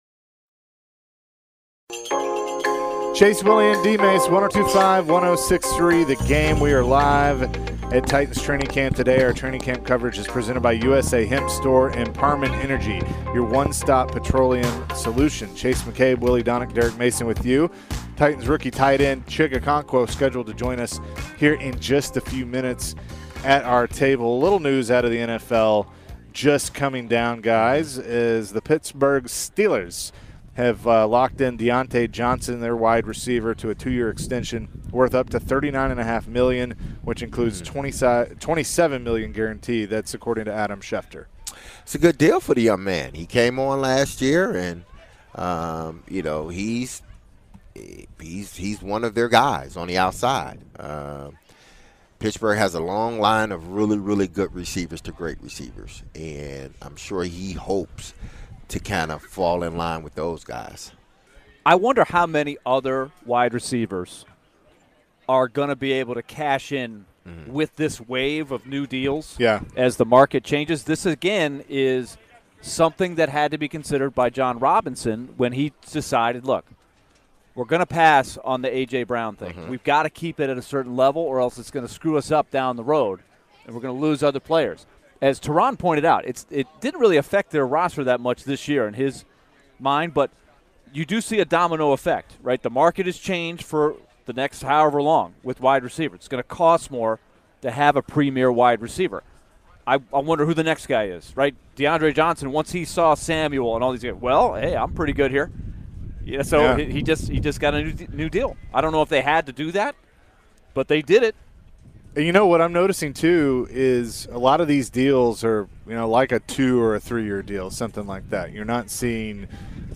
Chig Okonkwo Full Interview (08-04-22)